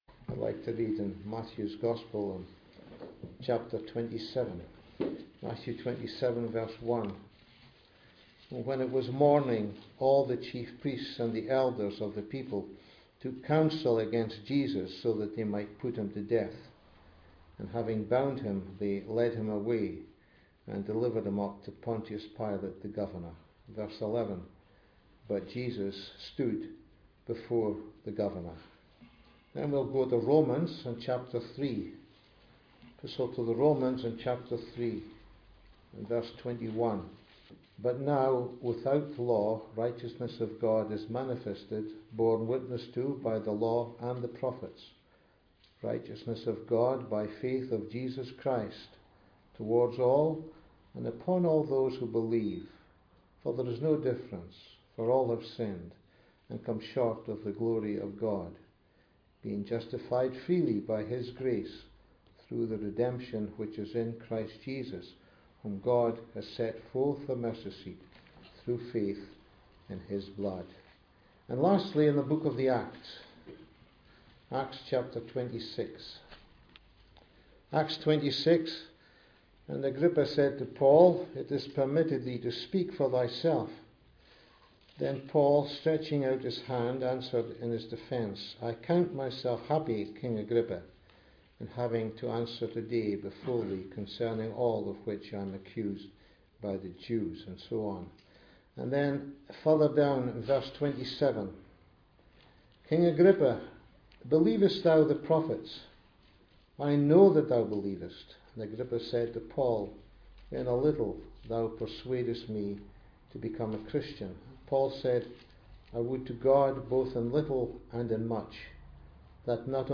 In this Gospel Preaching, you will hear of three prisoners. Two of them were rightly judged. But one was wrongly accused.